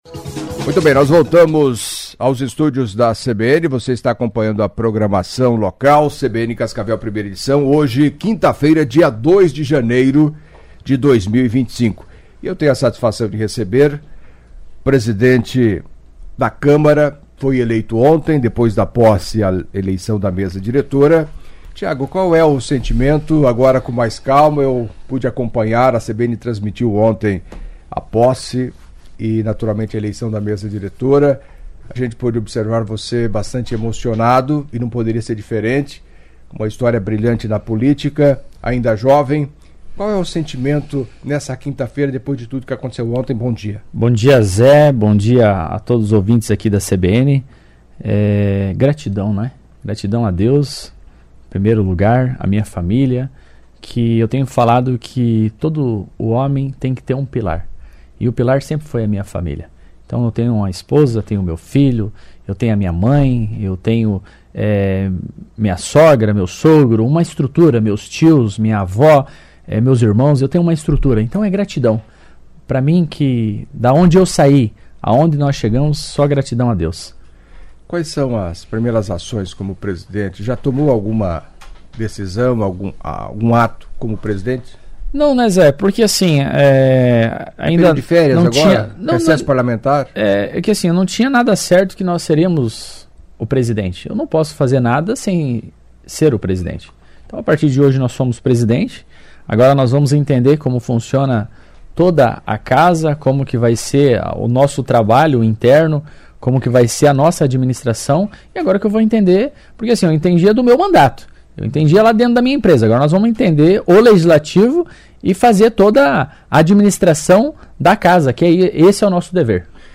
Em entrevista à CBN Cascavel nesta quinta-feira (02) Tiago Almeida, eleito presidente da Câmara para o biênio 2025/2026, destacou os caminhos percorridos para chegar ao comando do Poder Legislativo da quinta maior cidade do Paraná, acompanhe.